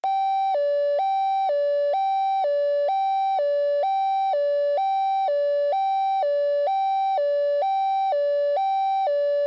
Звуки охранной сигнализации
Тревожный сигнал при выявлении нарушителя